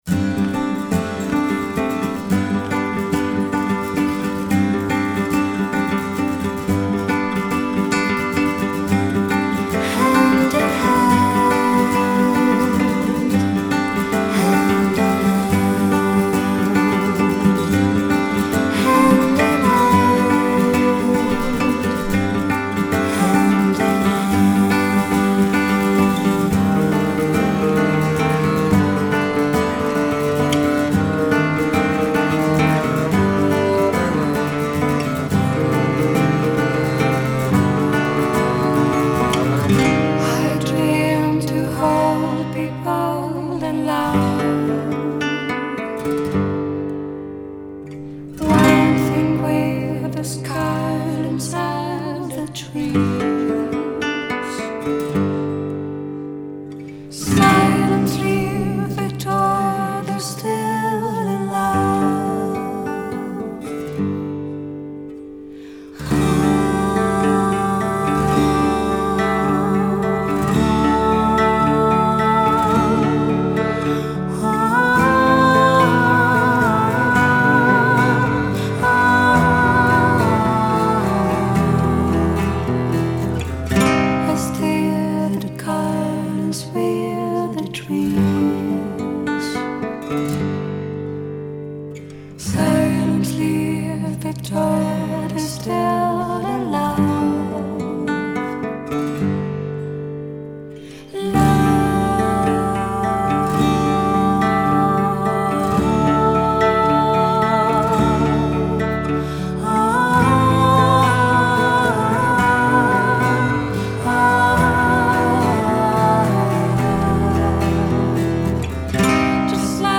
地中海の叙情と哀愁溢れる感動作キターー！
guitars
vocals, cello
violin
piano
drums